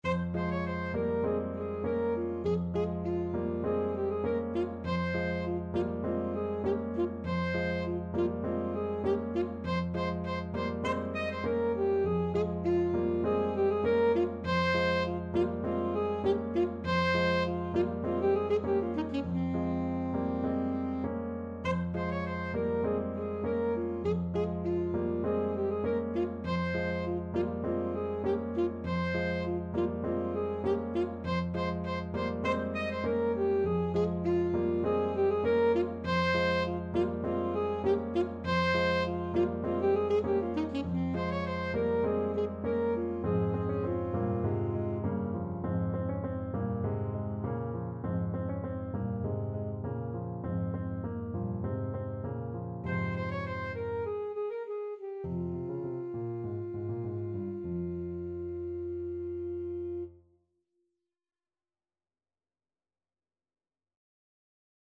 Classical Tchaikovsky, Pyotr Ilyich U Vorot (At the Gate) from 1812 Overture Alto Saxophone version
F minor (Sounding Pitch) D minor (Alto Saxophone in Eb) (View more F minor Music for Saxophone )
Allegro (View more music marked Allegro)
4/4 (View more 4/4 Music)
Classical (View more Classical Saxophone Music)